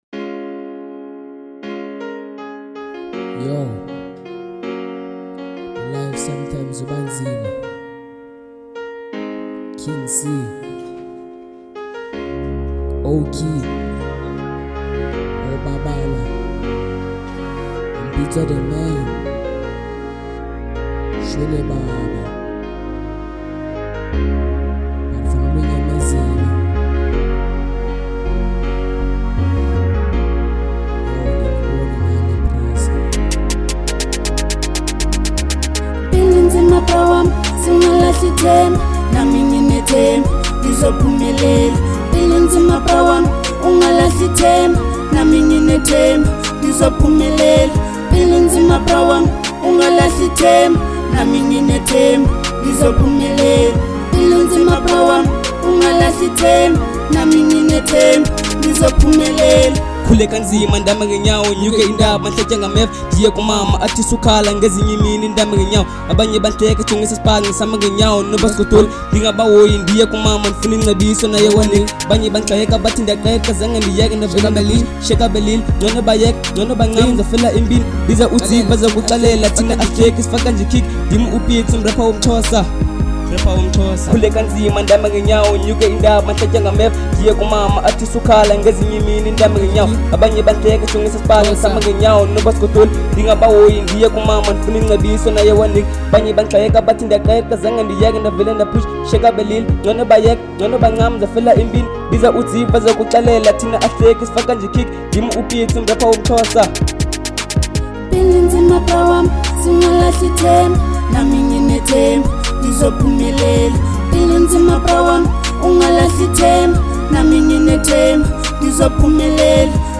05:18 Genre : Hip Hop Size